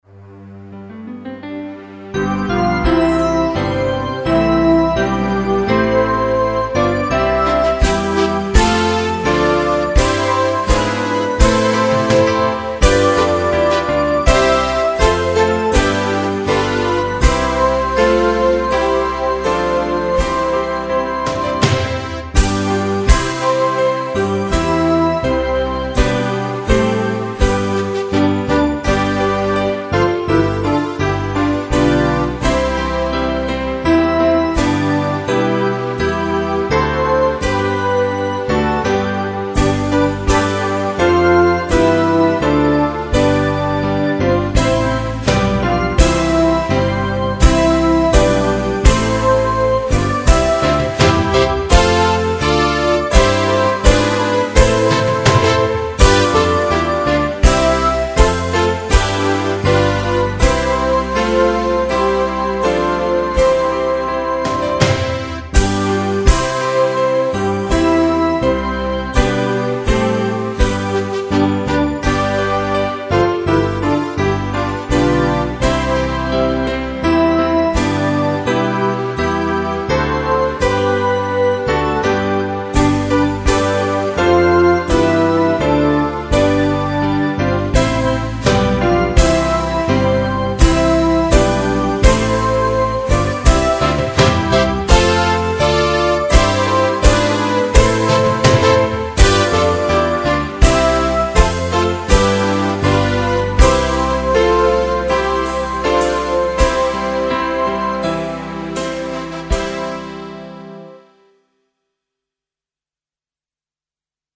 Ende Partangiangan